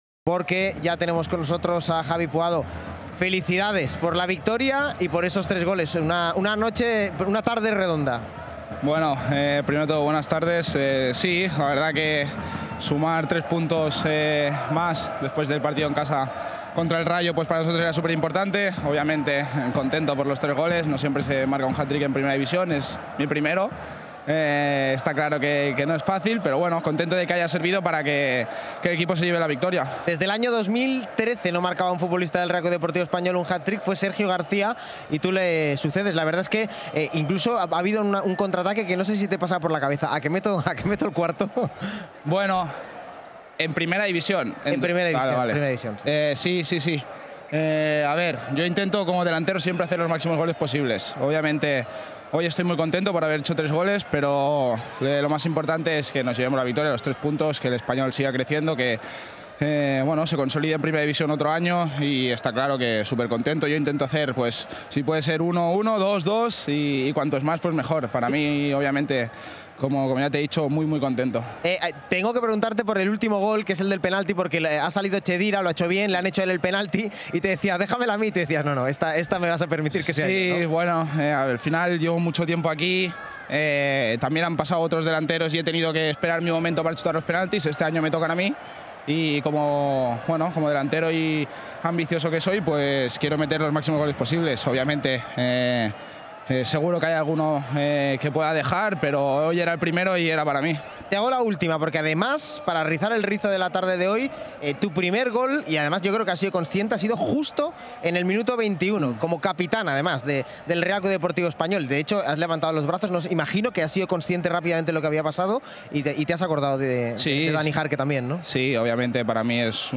Javi Puado ha valorado para las cámaras de las televisiones con derechos el RCD Espanyol – Deportivo Alavés disputado este sábado en el RCDE Stadium, que ha decidido con un hat-trick.